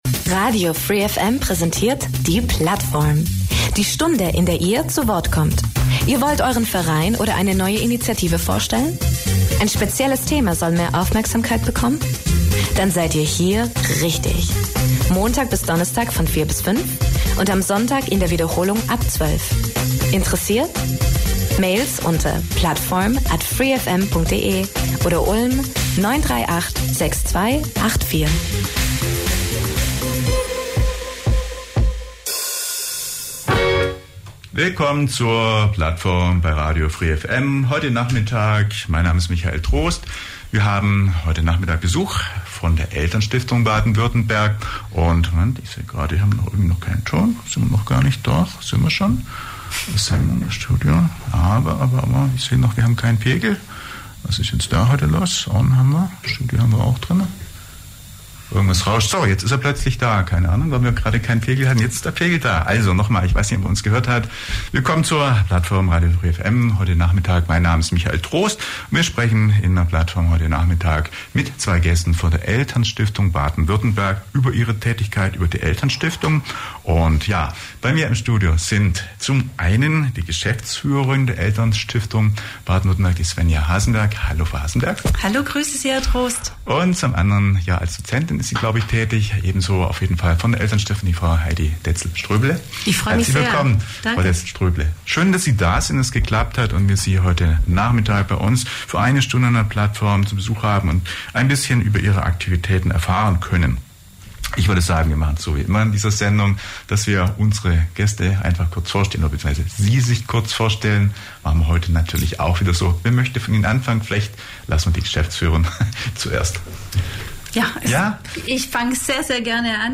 Zum 20. Mal steht die Ulmer Friedenswoche an und drei Aktive aus der Friedensbewegung sind zu Gast in der Plattform. Ihr hört Neues aus der Friedenswerkstatt, den Stand ihrer neuen Website und ihres Newsletters sowie die Radio-Pläne der Ulmer Friedensbewegung.